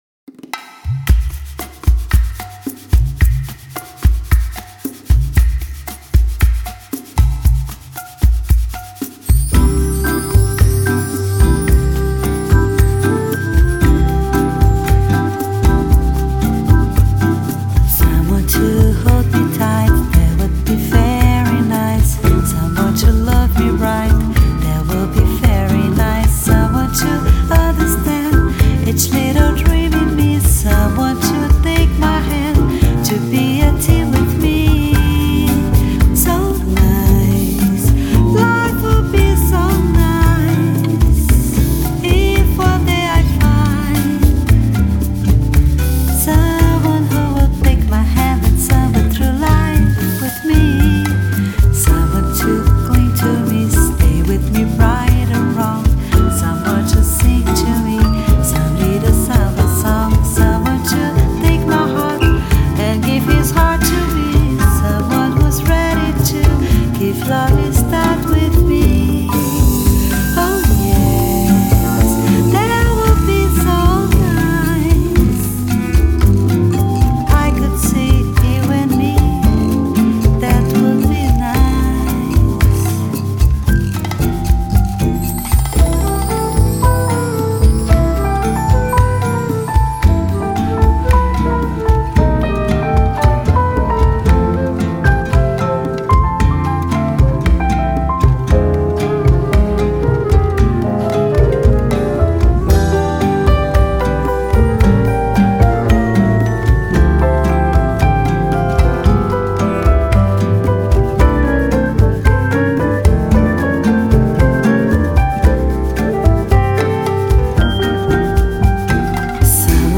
if not all three sultry smooth jazz/pop tracks